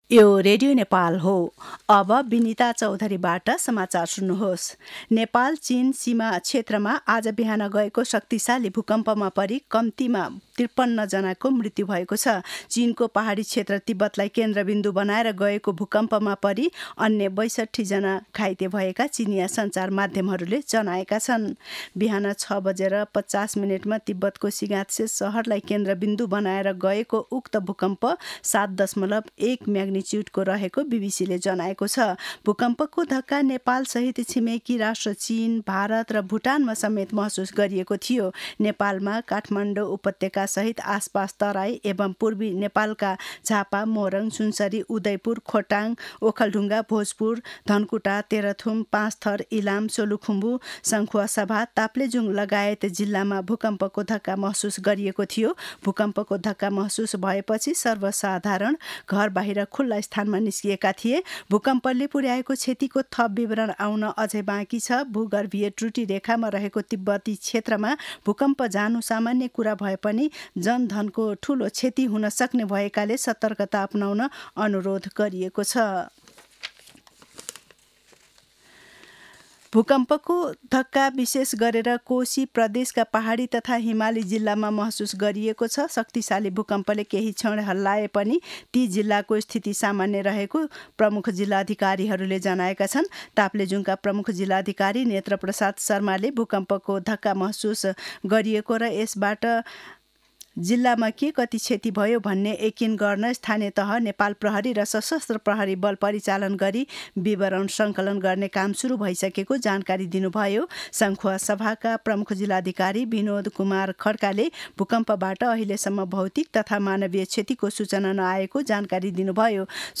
मध्यान्ह १२ बजेको नेपाली समाचार : २४ पुष , २०८१